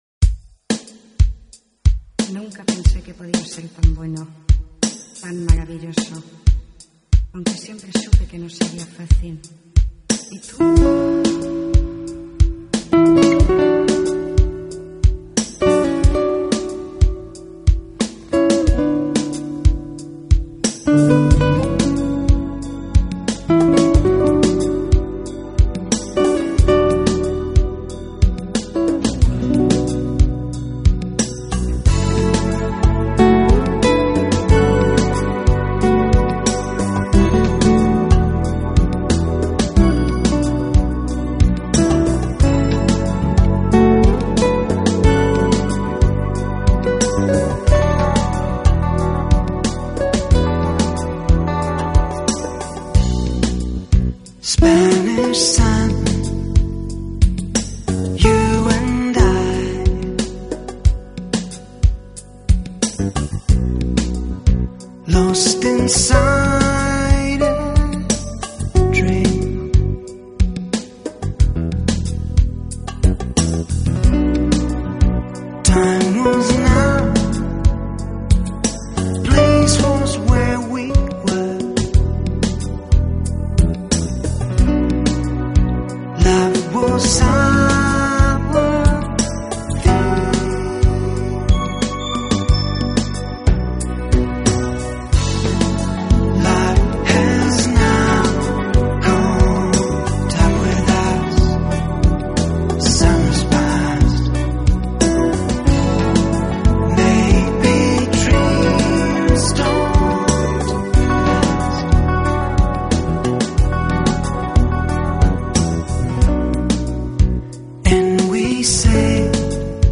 专辑风格：Jazz / Smooth Jazz